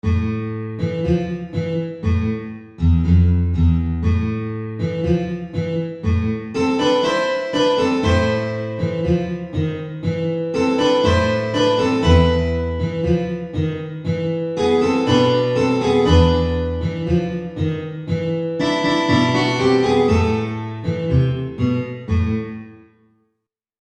Ensemblemusik
Trio
Gitarre (3)